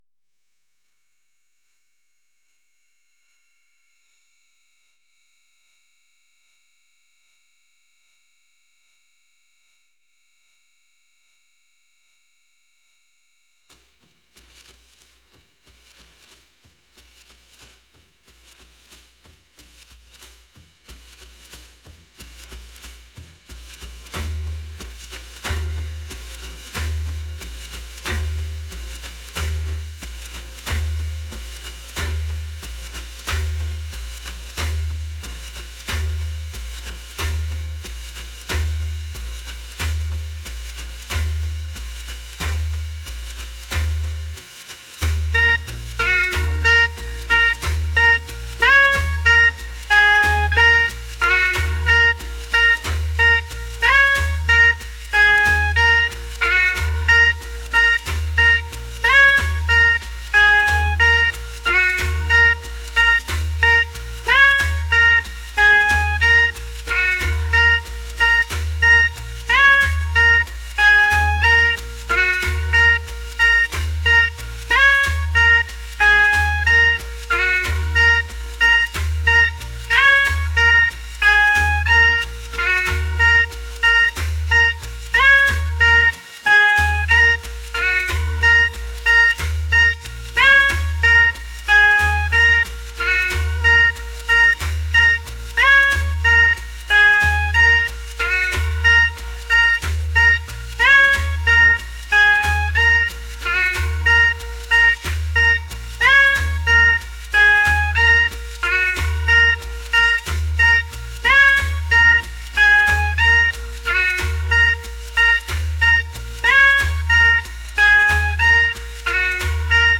rhythmic | world